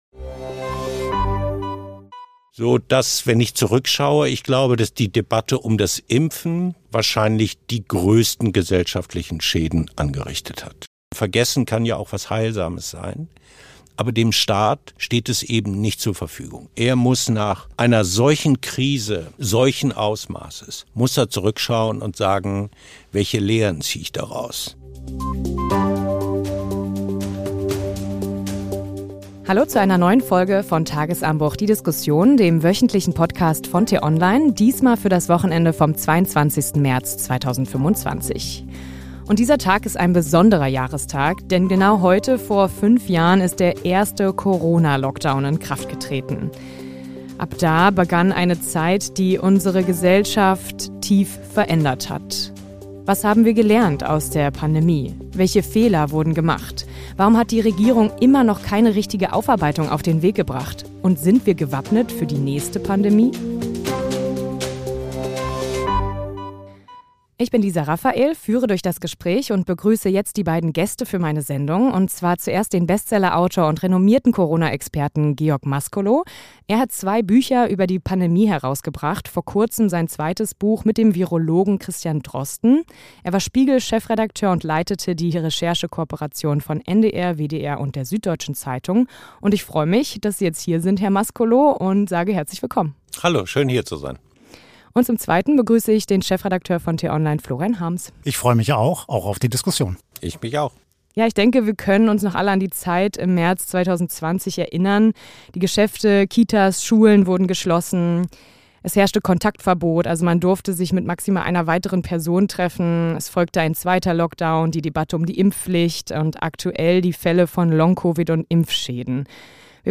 Im Gespräch mit Bestseller-Autor und Corona-Experte